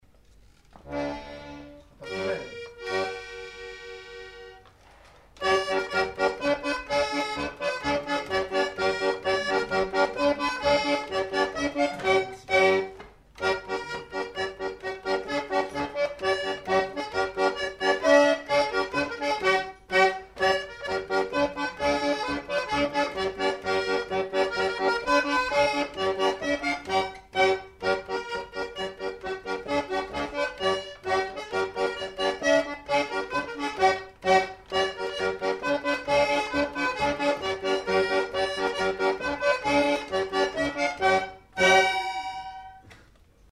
Lieu : Pyrénées-Atlantiques
Genre : morceau instrumental
Instrument de musique : accordéon diatonique
Danse : quadrille (4e f.)